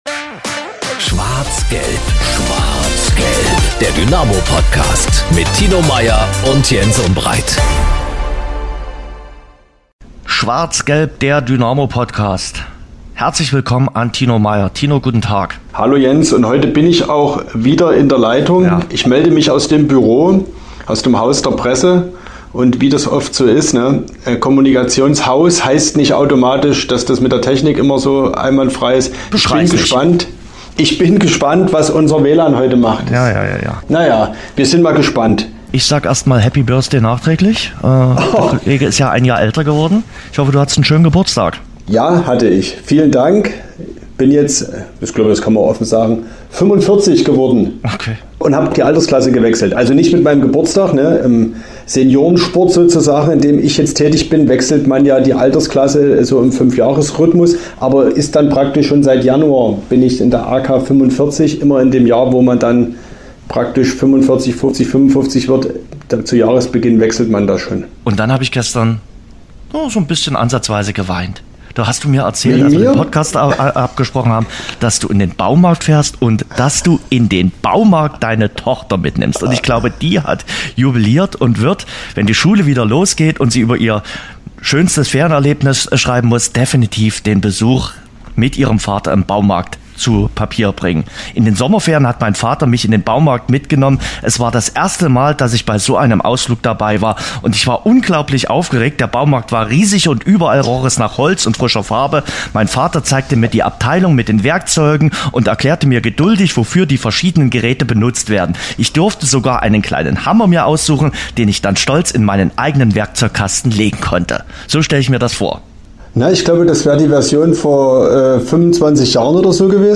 Wir sprechen mit dem Sportjournalisten...